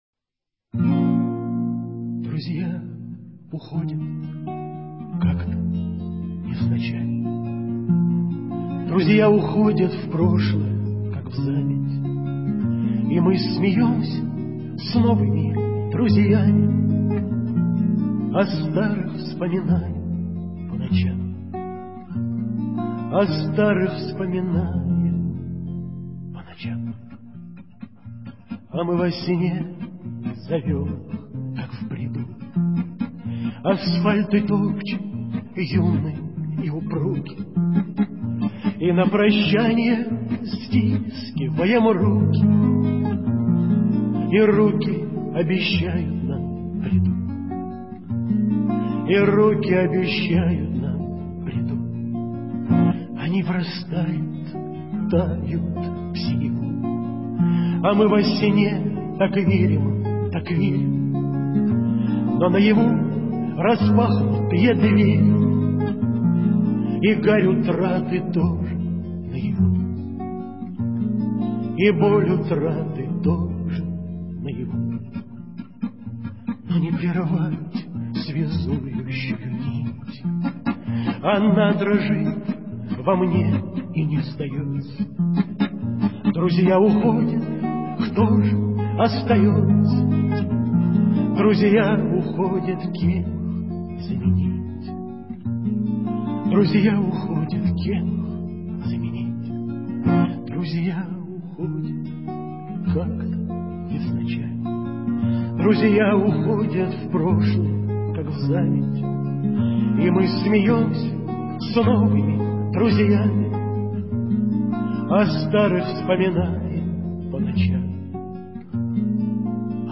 В исполнении автора